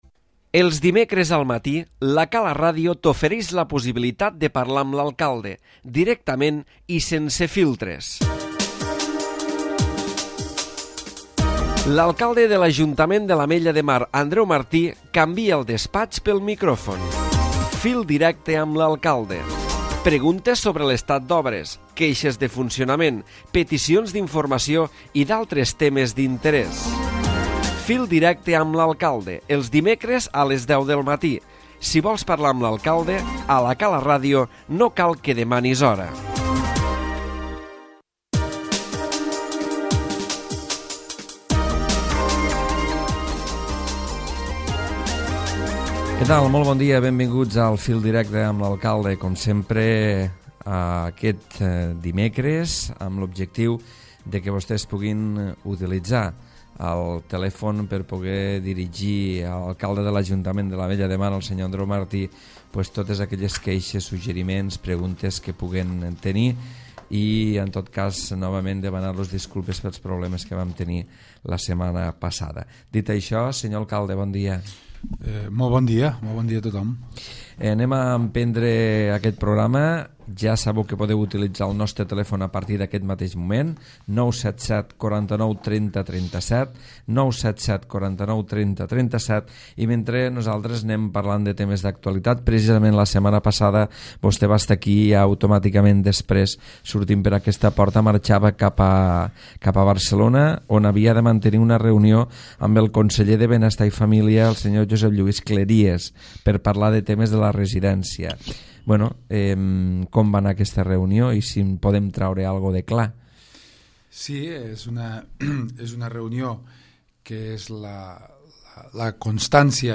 L'Alcalde Andreu Martí ha parlatr al Fil Directe de la residència per a la gent gran i de la re-naturalització de Port Olivet i l'Estany entre d'altres temes.